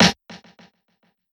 Snare (Garden Shed).wav